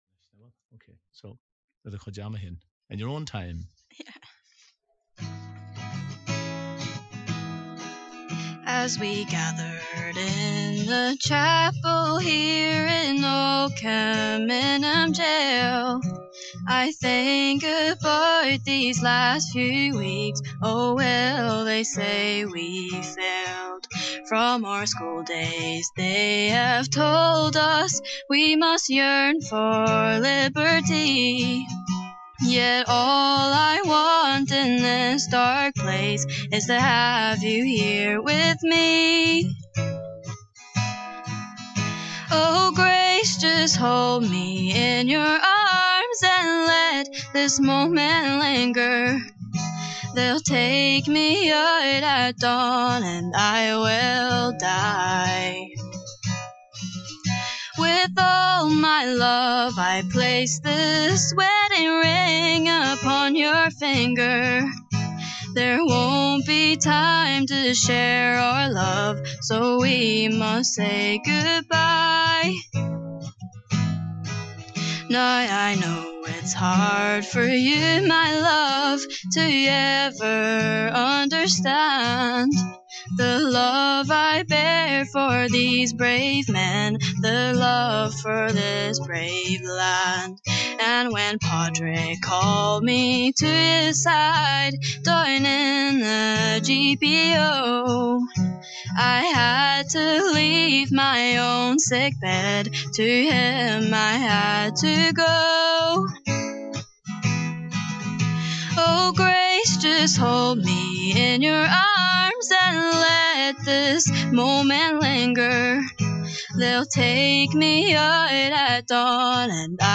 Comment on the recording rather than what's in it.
ceol beo